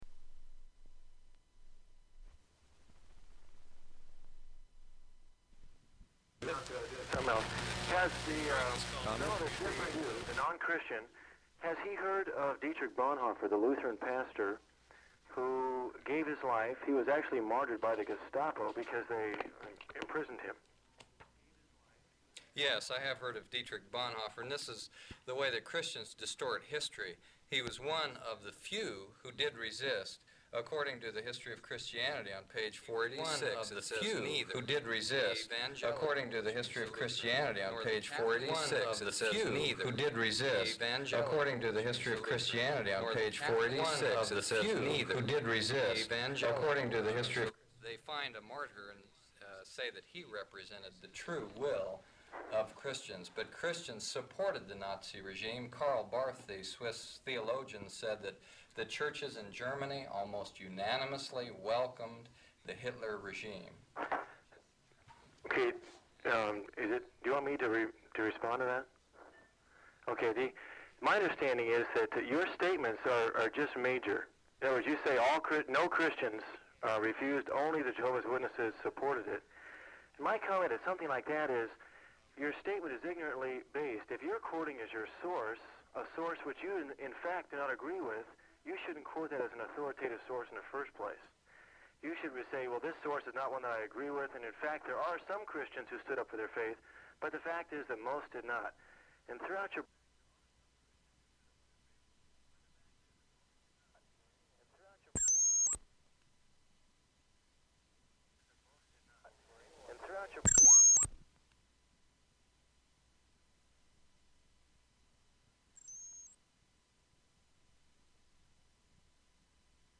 Form of original Open reel audiotape